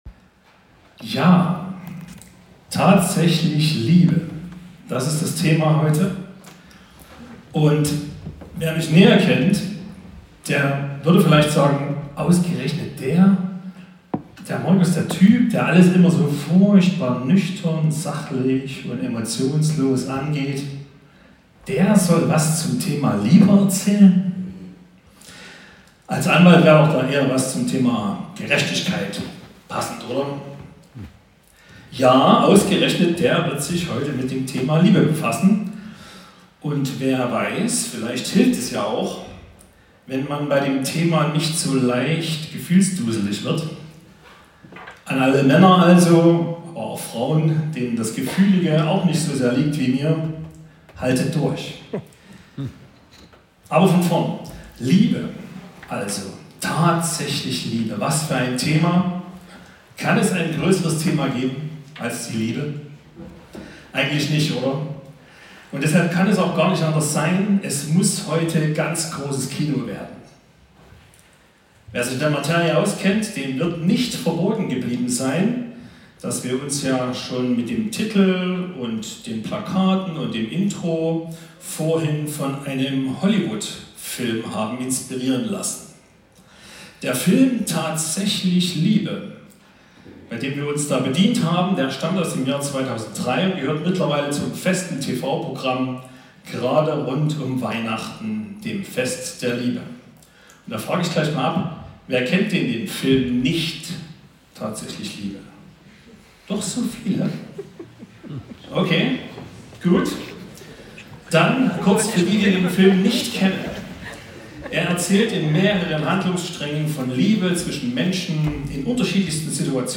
Eine Predigt über die Unterschiede der menschlichen und göttlichen Liebe